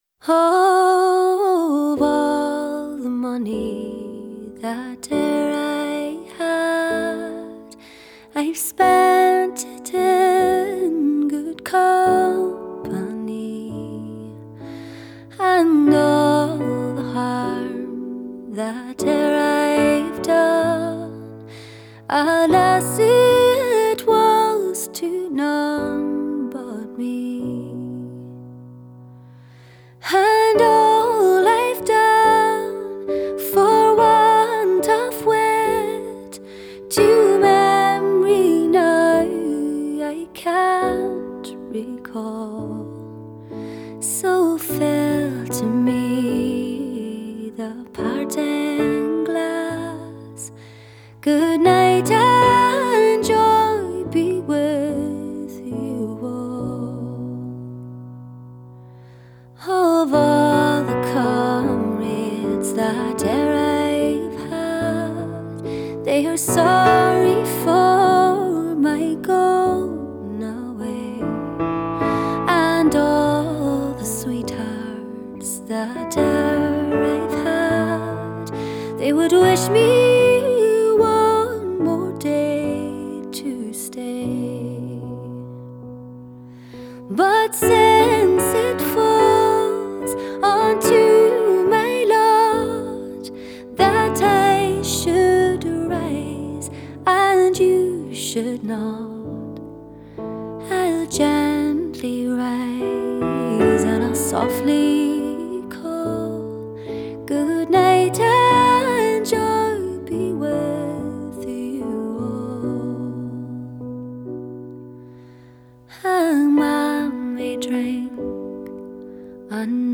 Genre: Folk / World /Celtic